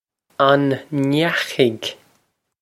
Pronunciation for how to say
On nyakh-ig?
This is an approximate phonetic pronunciation of the phrase.